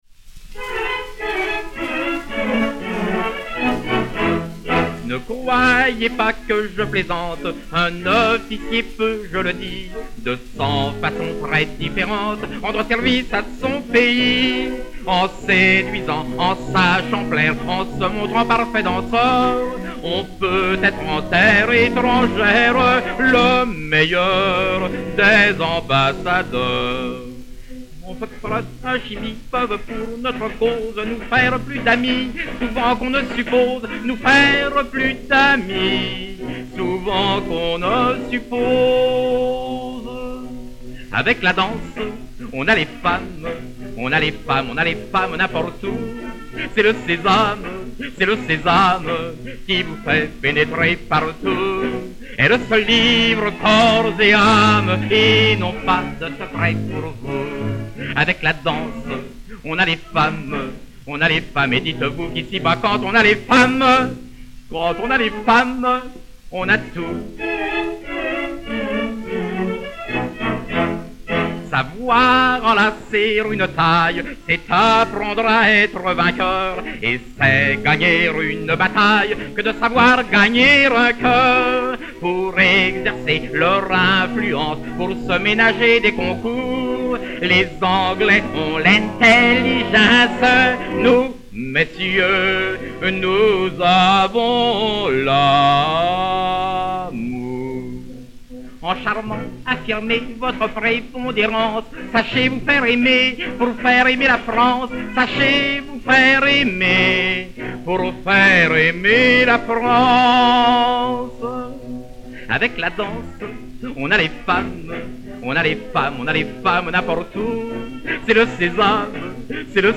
Chœurs et Orchestre dir.